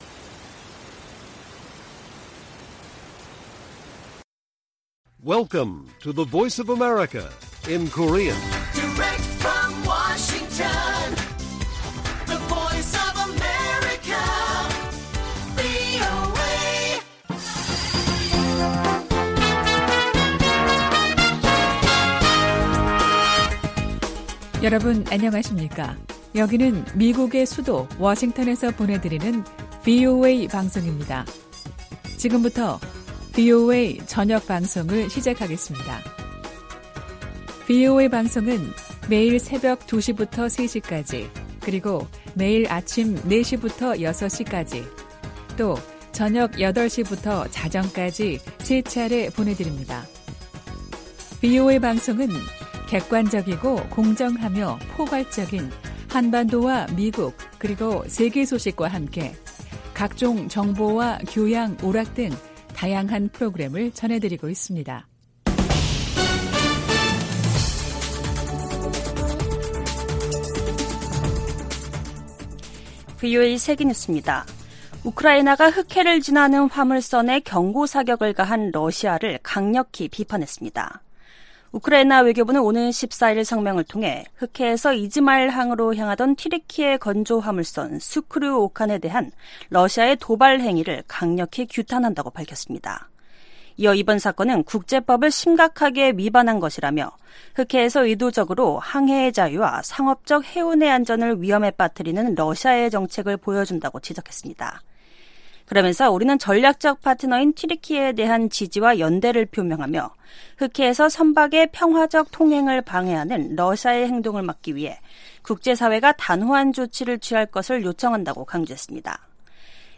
VOA 한국어 간판 뉴스 프로그램 '뉴스 투데이', 2023년 8월 14일 1부 방송입니다. 북한이 악의적 사이버 활동을 통해 안보리 제재를 회피하고 있다고 유엔 주재 미국 대표가 지적했습니다. 미 국방부는 11일 공개된 북한과 러시아 간 무기 거래 정황에 심각한 우려를 나타냈습니다. 오는 18일 열리는 미한일 정상회의에서 3국 군사훈련 정례화와 다양한 3국 간 협의체 구성 방안이 논의될 것으로 알려졌습니다.